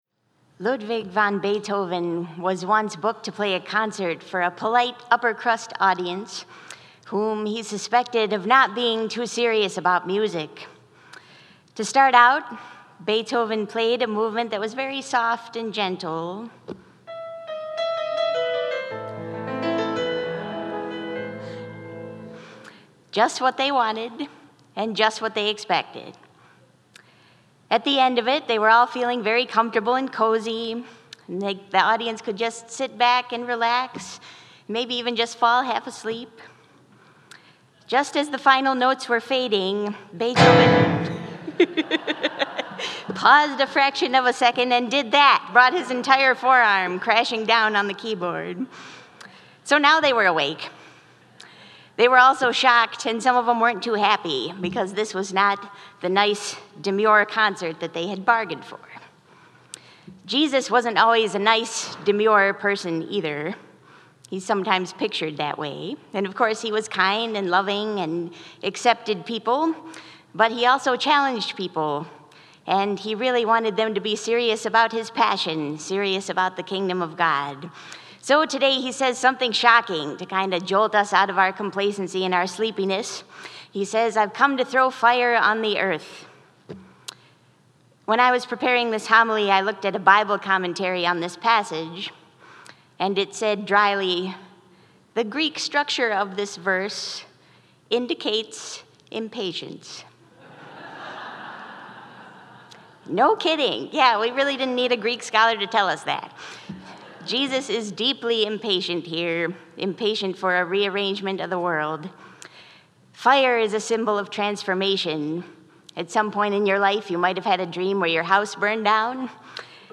2022 7:30 pm Loved your homily.
2022 6:44 pm A soothing homily peace Add a Comment Cancel Your email address will not be published.